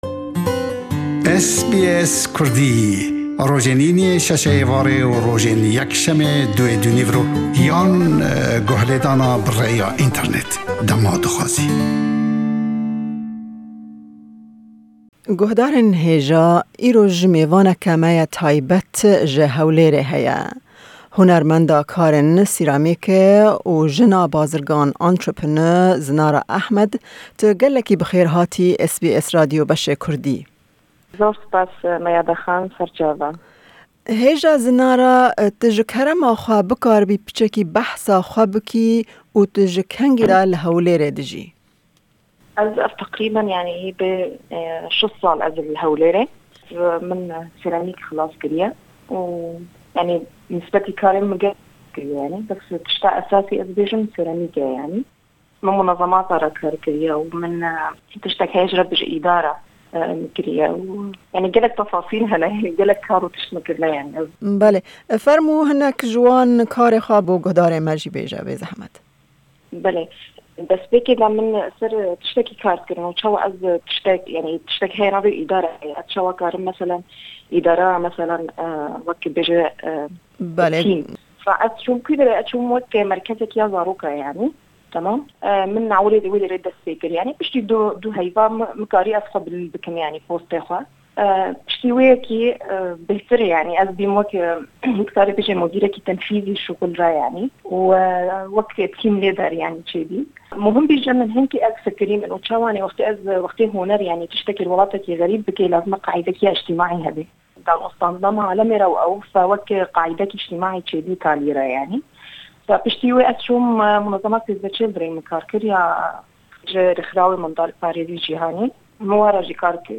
Me hevpeyvînek li ser karê wê, astengiyên hatin pêsh wê, projeyên wê û jiyana wê pêk anî.